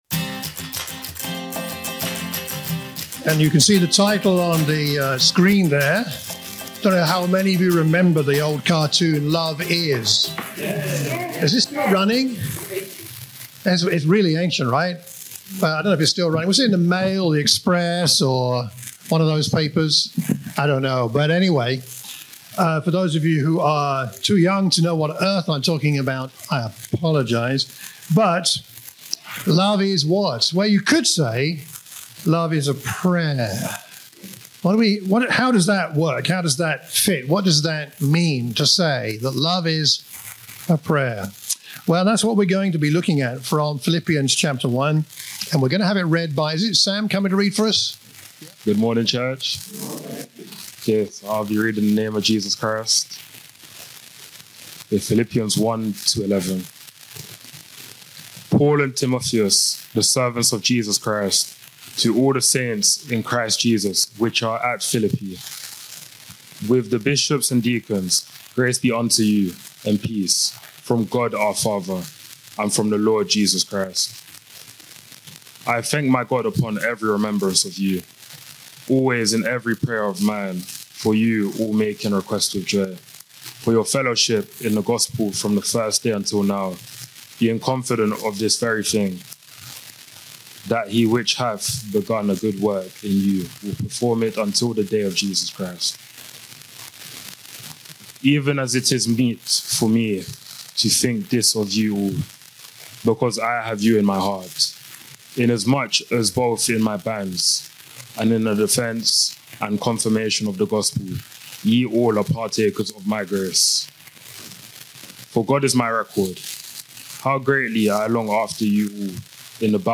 In this sermon from Philippians 1:1–11, we explore how Paul’s joyful prayers for the Philippians flowed from their shared life in the gospel and how his purpose-driven prayers called them to deeper love, knowledge, and discernment. Along the way, we’ll see that prayer is not just words—it’s the root that grows kingdom fruit in each other’s lives.